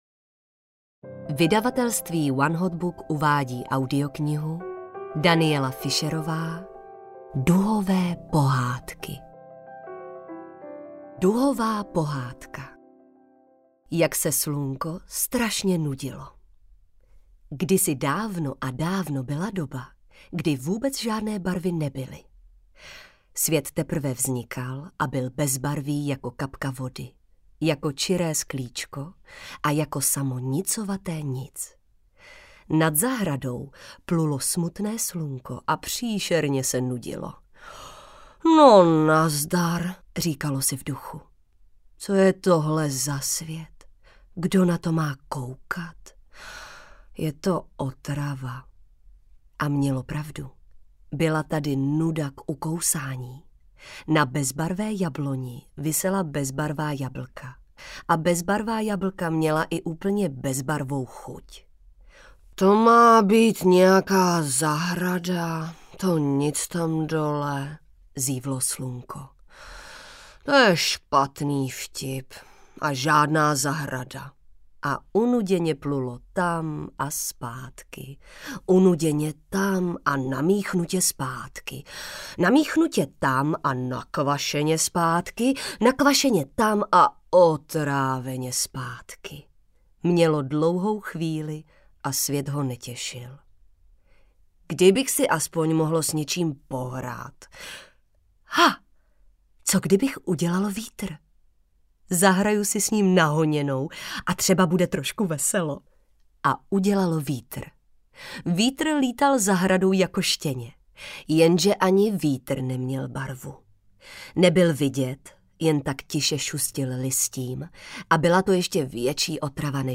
Duhové pohádky audiokniha
Ukázka z knihy